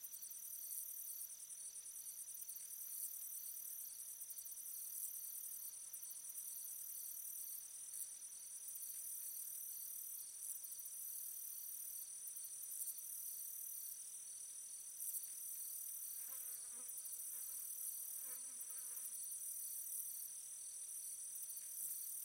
insectday_3.ogg